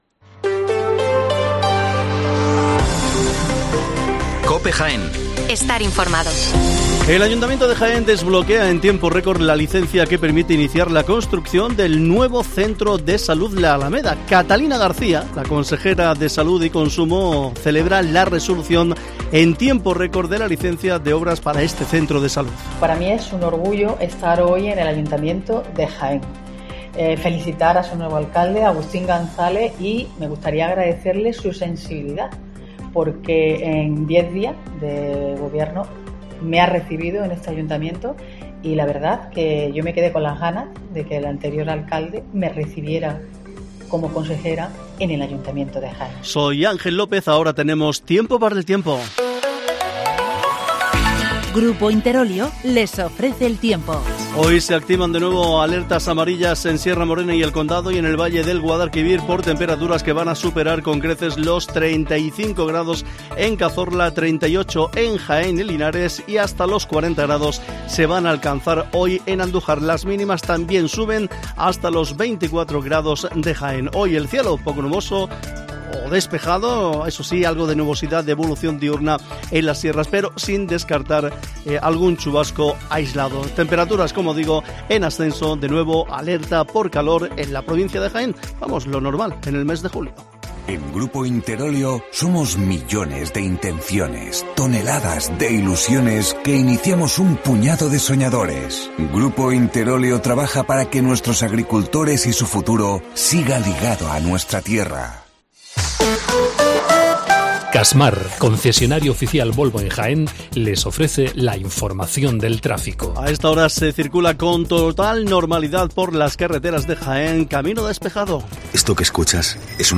Las noticias matinales en Herrera en COPE 8:24 horas